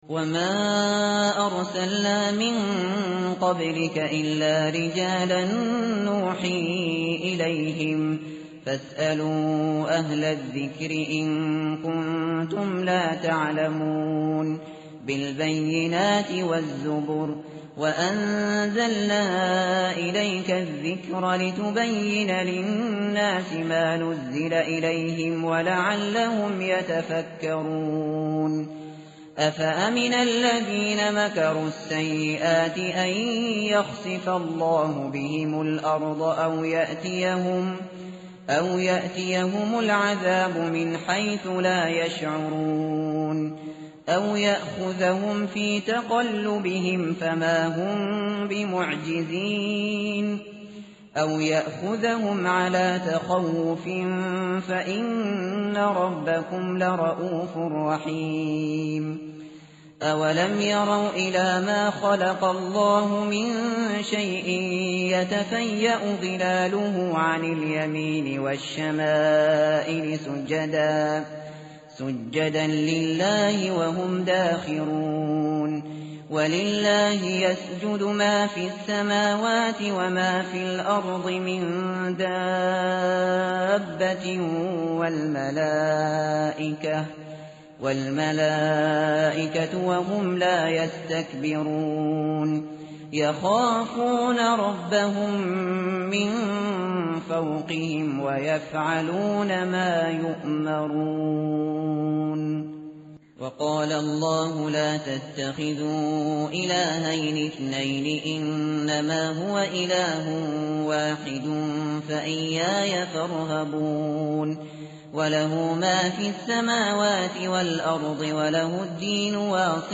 tartil_shateri_page_272.mp3